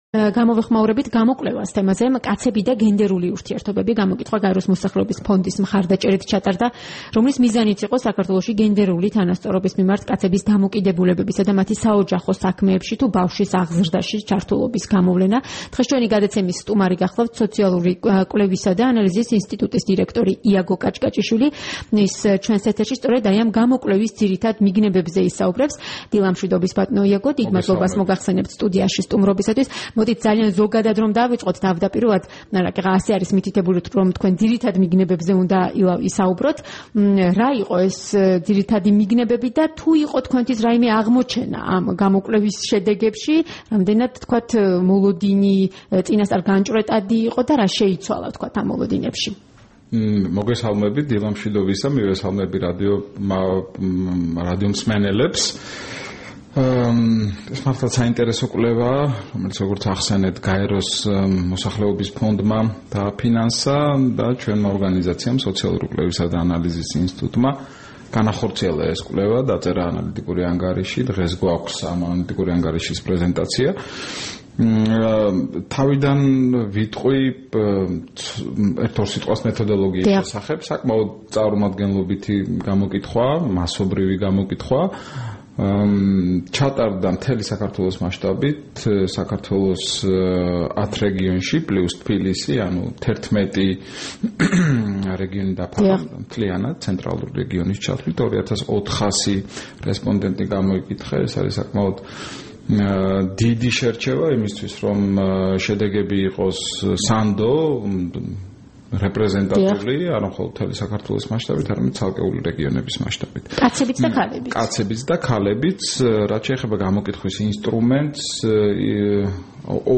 საუბარი